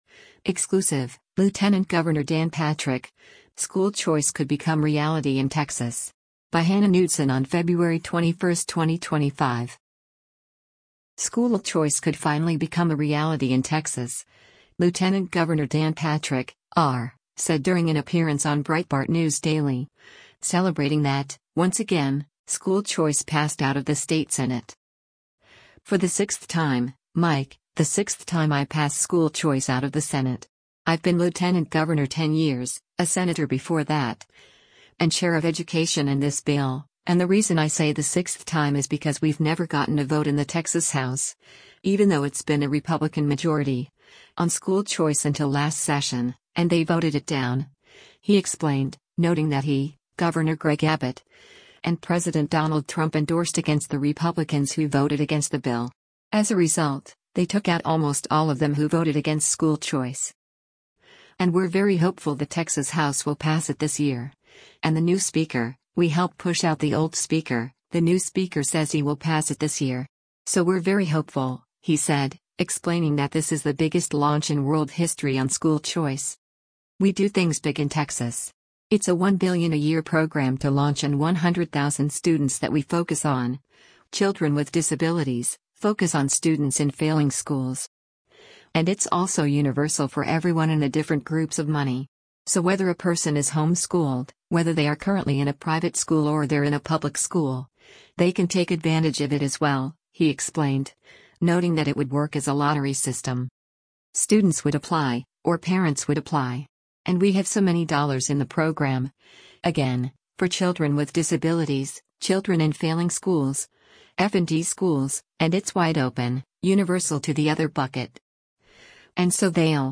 School choice could finally become a reality in Texas, Lt. Gov. Dan Patrick (R) said during an appearance on Breitbart News Daily, celebrating that — once again — school choice passed out of the state Senate.